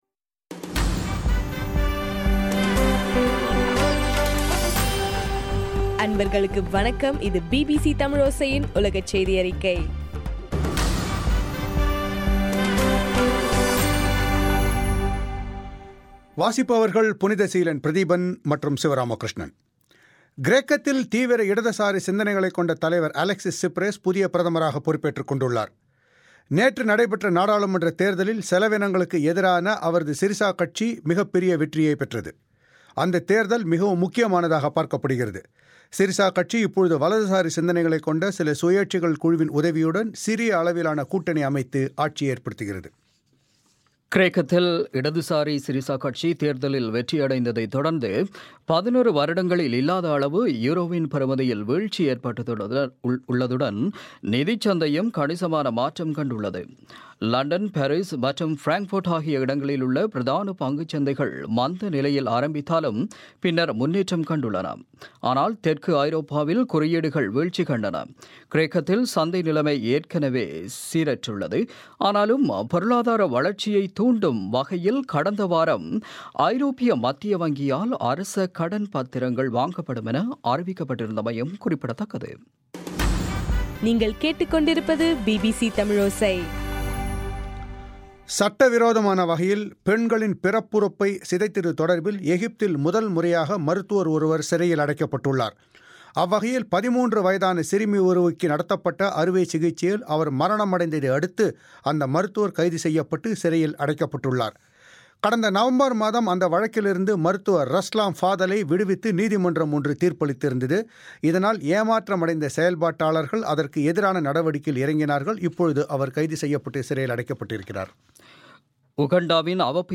ஜனவரி 26 2015 பிபிசி தமிழோசையின் உலகச் செய்திகள்